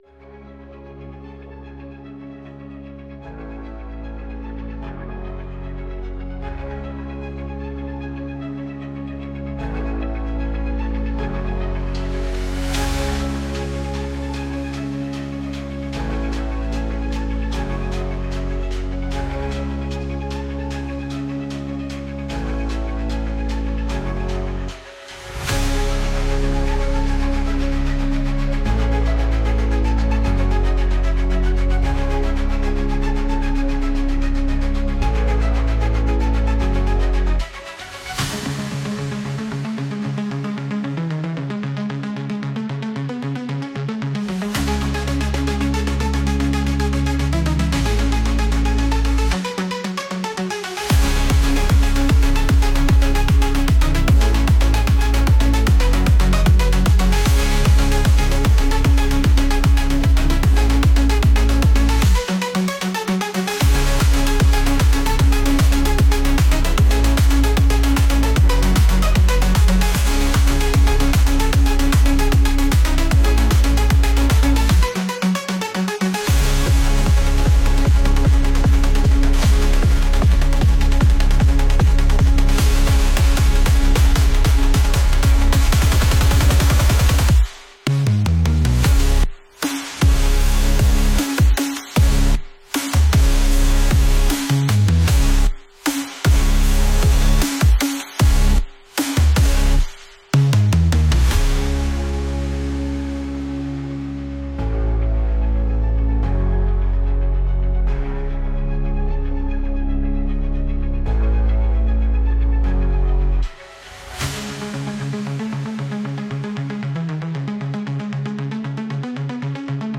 Our special masculine frequency MP3 file is tuned to boost your masculine energy, enhance you vitality, get motivation to achieve your goals and dreams.
frequency therapy to boost your masculine energy